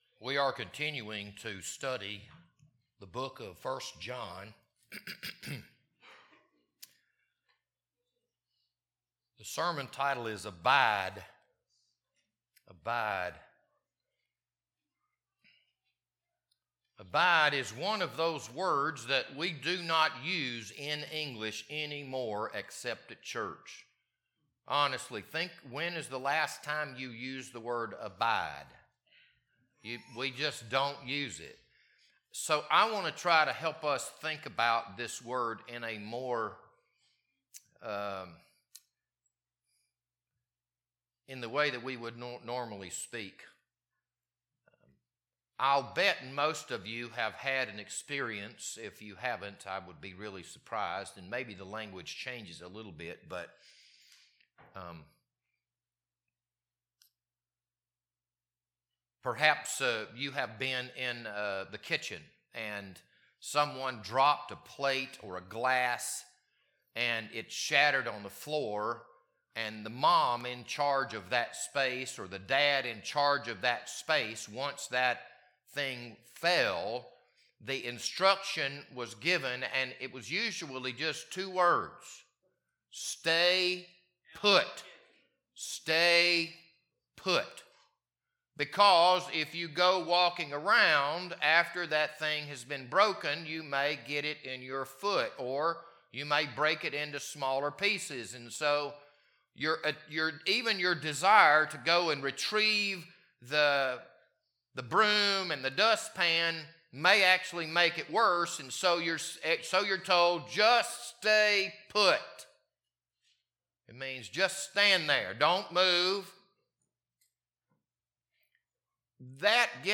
This Sunday morning sermon was recorded on March 22nd, 2026.